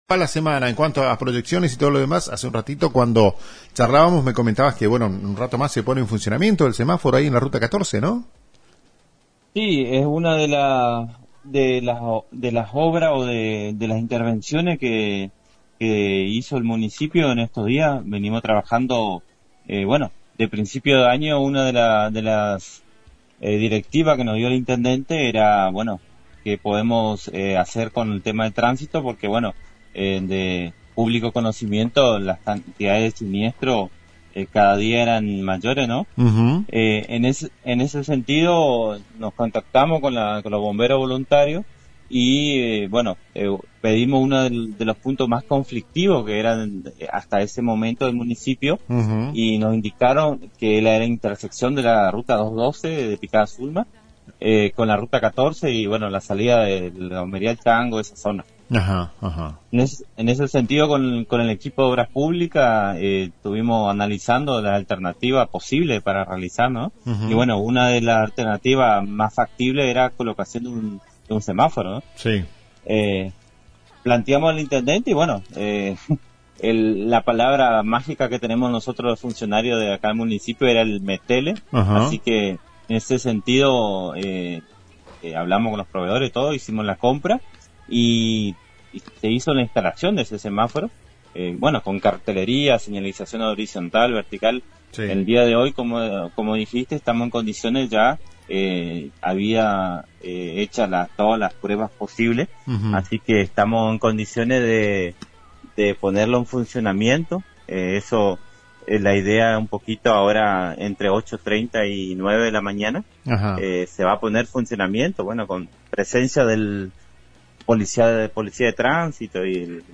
Entrevista: Cristian Bitancort, secretario de Obras Públicas, Municipalidaad de San Vicente -